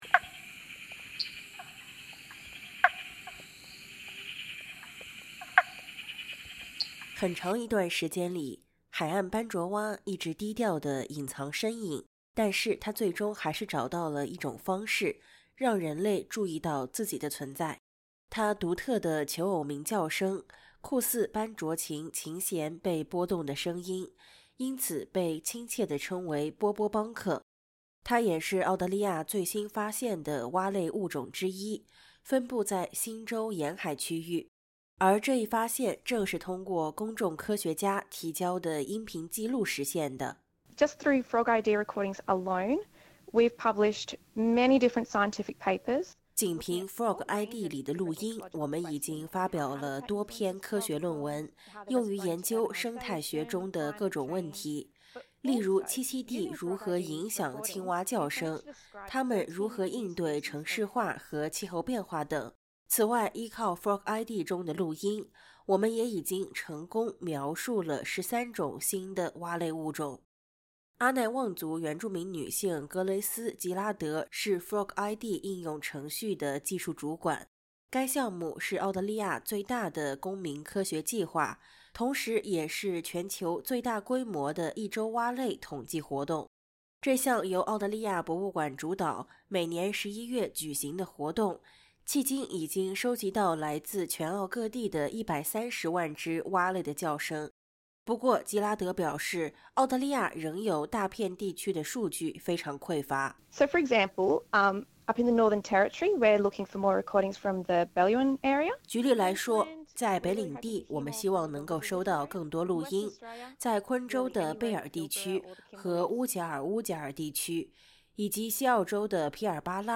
目前，已有130万只蛙类的叫声被记录在FrogID应用程序中。由澳大利亚博物馆主导的这一项目，已经促成了13种澳大利亚蛙类新物种的发现，其中有4种在过去一年被科学界正式认定为“全新物种”。点击音频，收听完整报道。